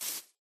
Sound / Minecraft / step / grass3.ogg
grass3.ogg